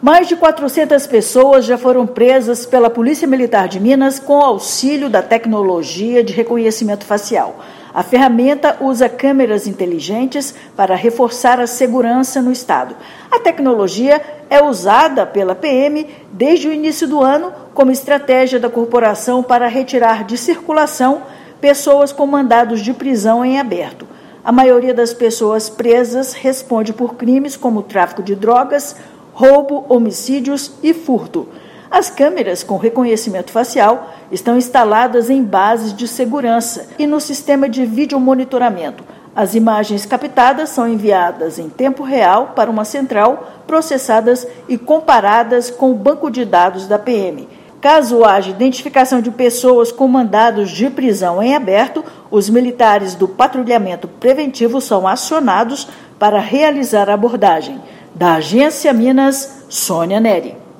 Tecnologia está sendo utilizada desde o início do ano e tem como objetivo retirar de circulação indivíduos com mandados de prisão em aberto. Ouça matéria de rádio.